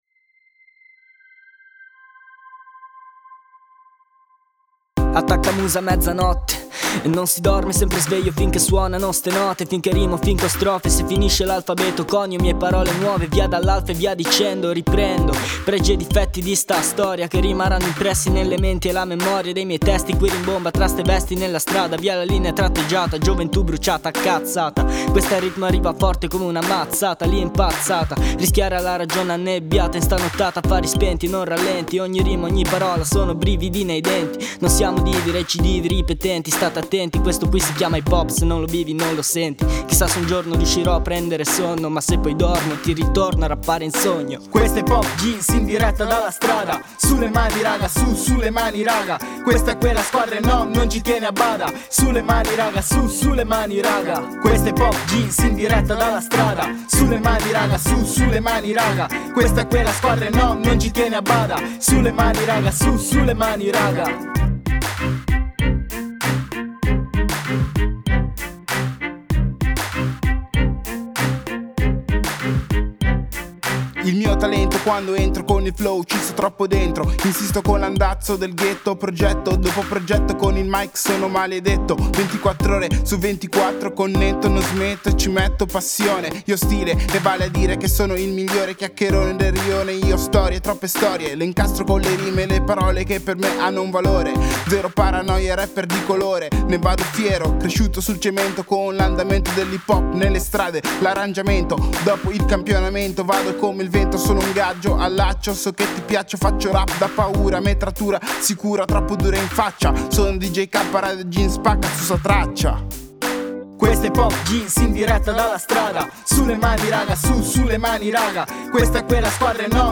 rapper
hanno composto e registrato nel nostro studio questo brano originale che è anche sigla della trasmissione.